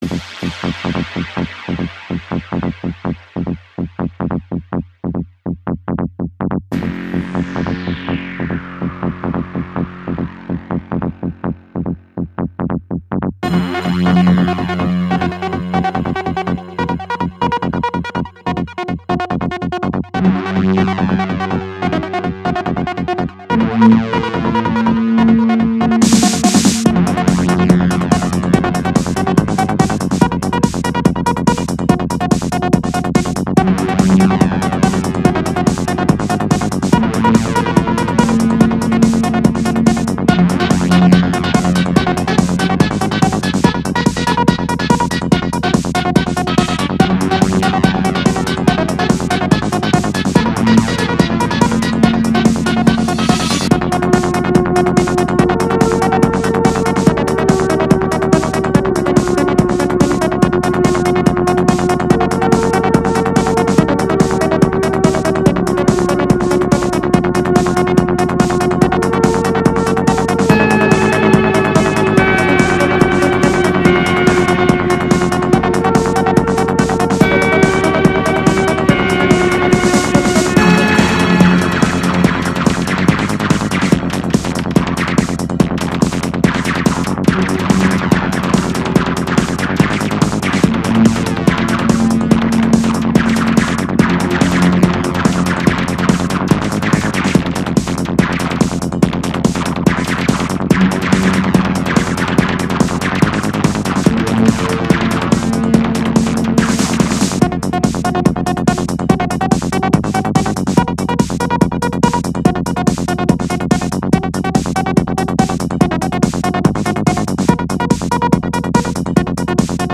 Fast-paced, very nice.